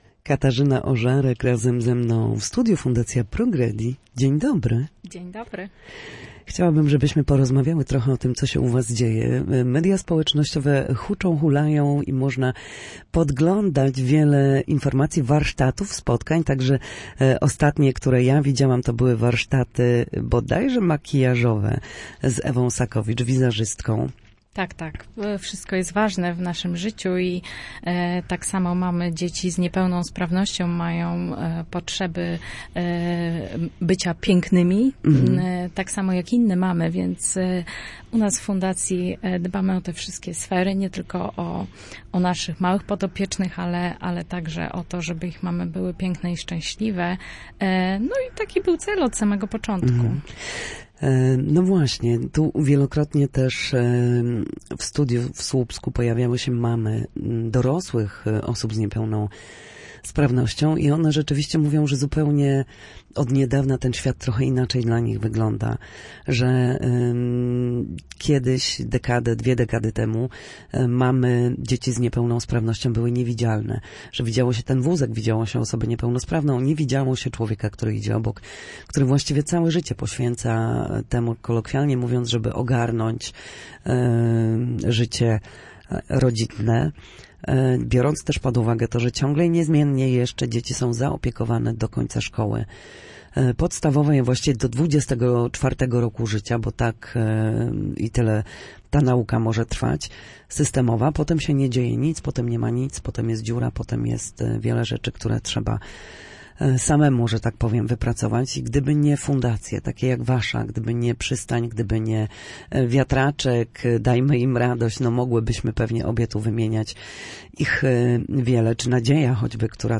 Na naszej antenie mówiła też między innymi o tym, z czym mierzą się rodzice i dlaczego wsparcie społeczne jest dziś tak ważne.